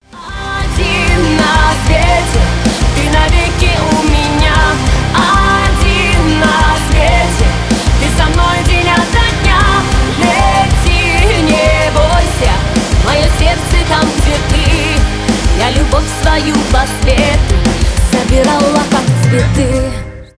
pop rock
поп